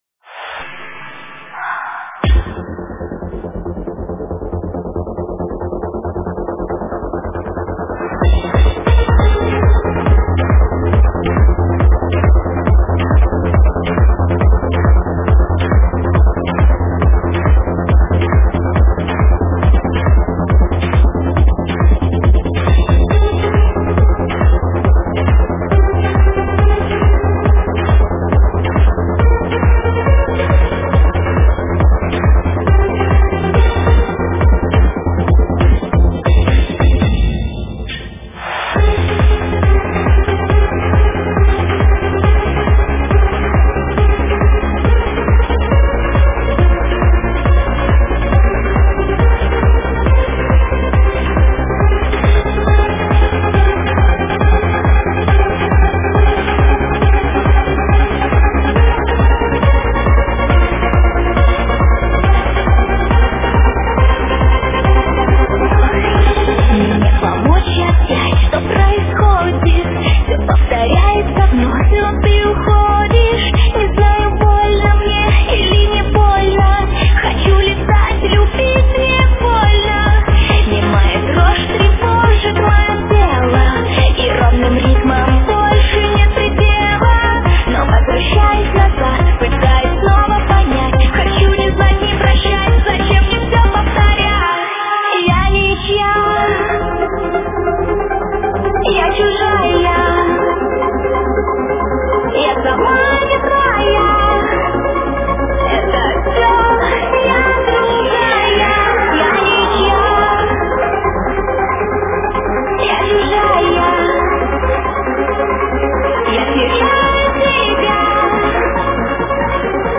Жанр:Vocal-Trance